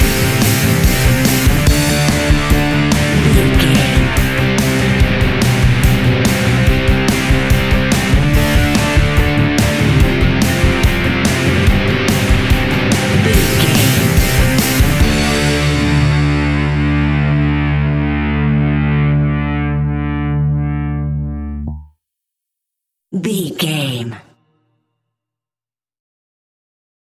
Epic / Action
Fast paced
Mixolydian
hard rock
blues rock
Rock Bass
heavy drums
distorted guitars
hammond organ